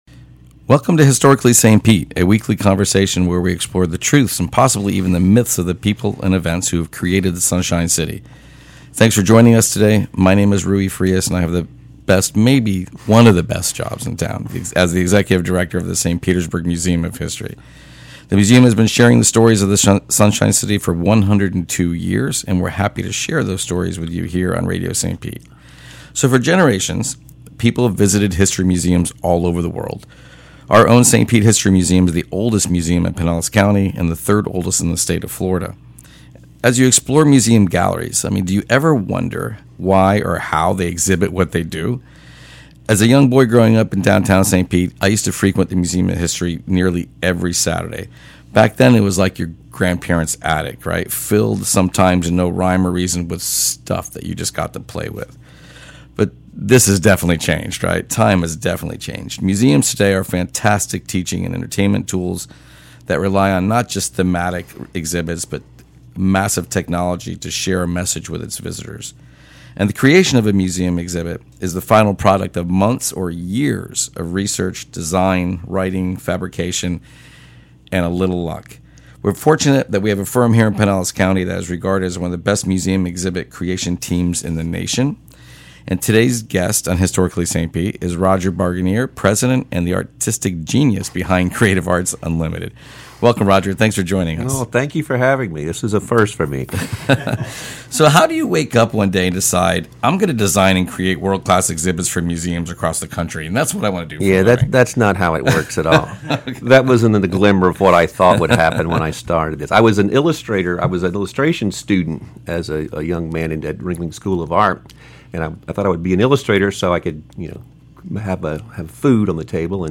Historically St Pete is a weekly conversation where we explore the truths, and possibly the myths of the people and events that created the Sunshine City.